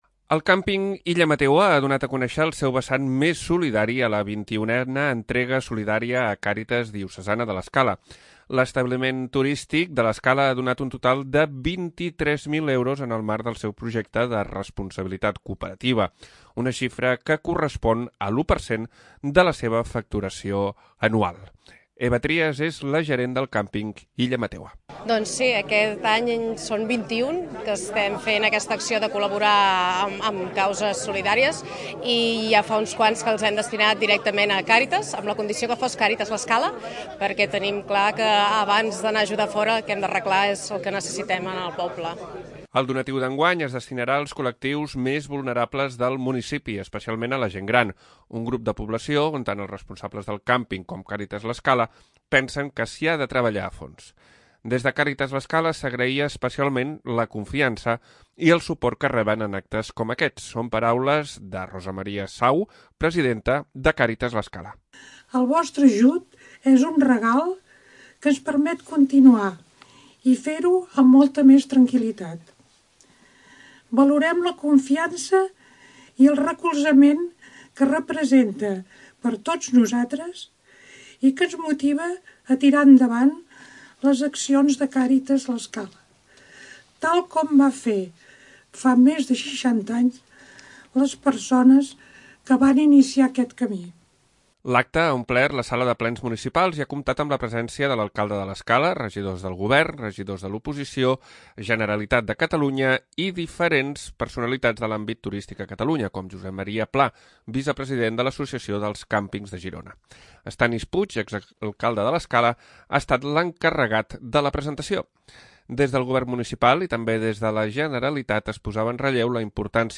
L'acte s'ha fet a la sala de plens de l'Ajuntament, que va quedar petita entre públic, convidats, govern i oposició.